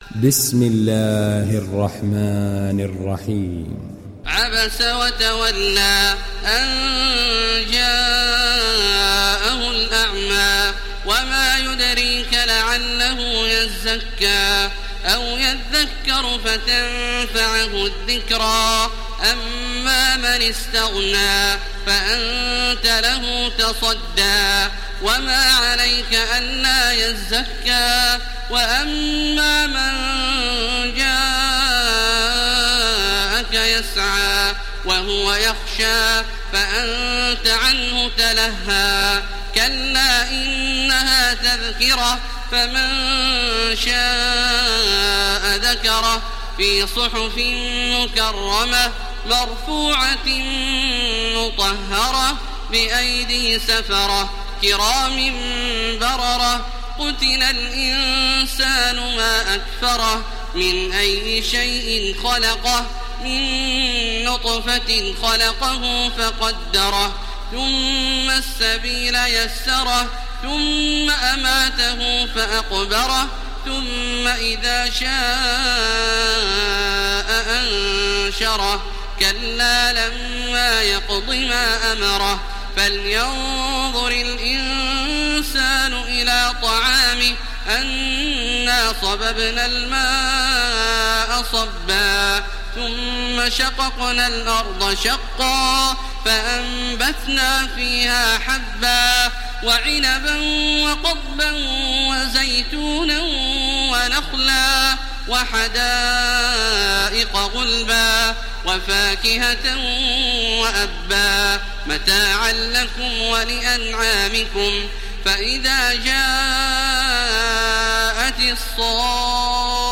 ডাউনলোড সূরা ‘আবাসা Taraweeh Makkah 1430